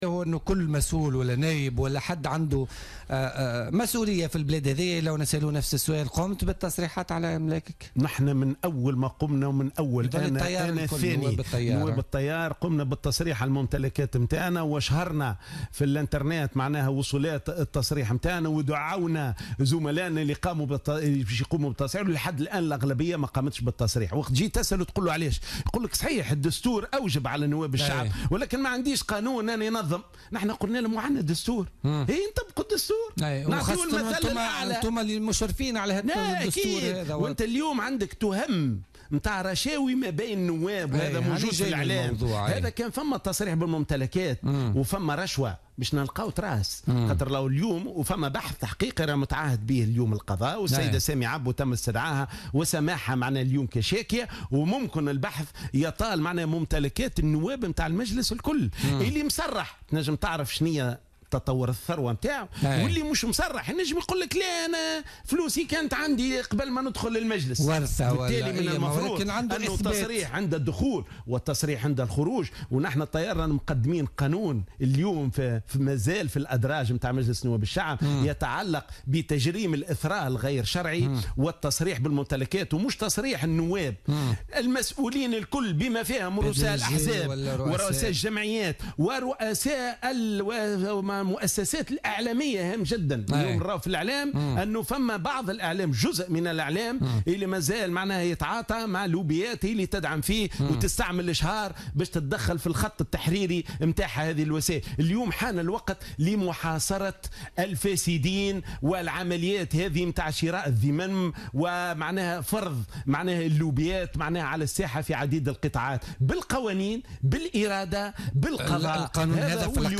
وأضاف الشواشي ضيف برنامج "بوليتيكا" لليوم الخميس 6 أكتوبر 2016 أن عدم تصريح النواب على ممتلكاتهم عند الدخول والخروج من المجلس لن يمكن من التحقيق في شبهات الفساد والرشاوي وشراء الذمم تحت قبة البرلمان.